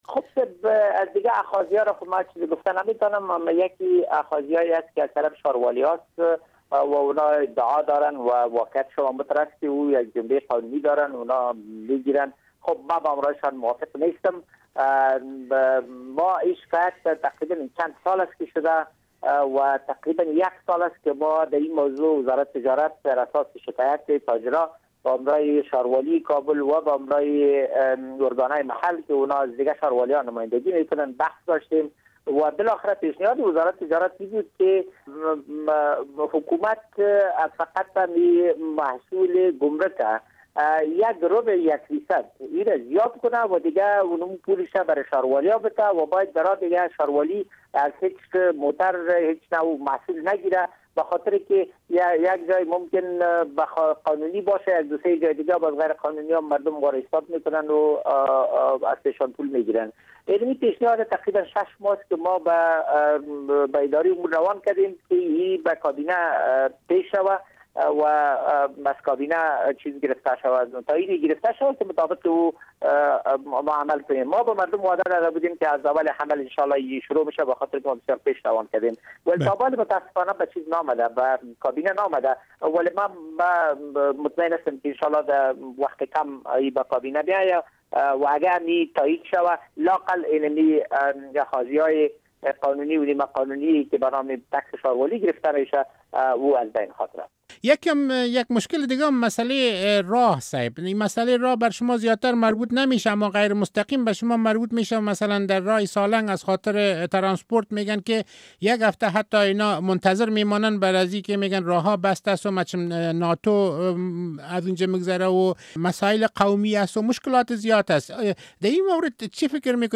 مصاحبه با وزیر تجارت در مورد اقدامات بخاطر حل مشکلات راننده گان وسایط ترانسپوراتی